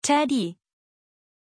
Pronunciation of Teddie
pronunciation-teddie-zh.mp3